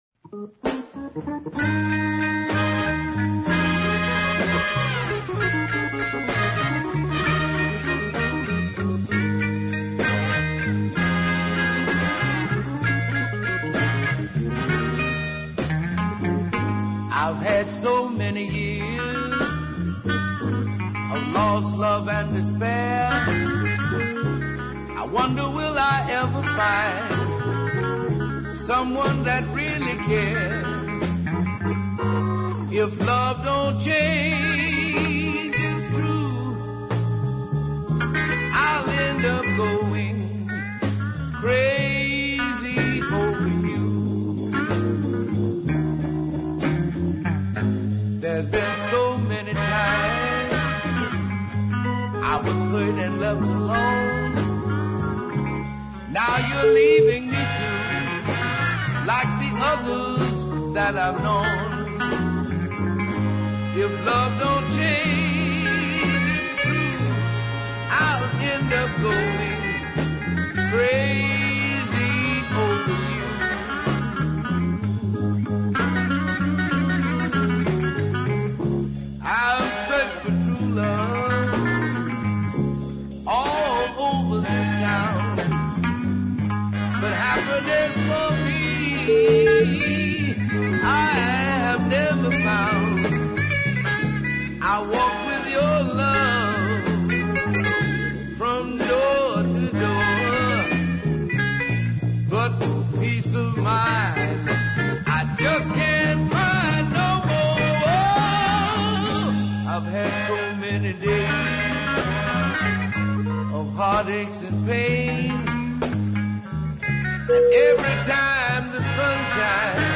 Chicago singer
big bluesy ballad
an organ is prominent on this one as well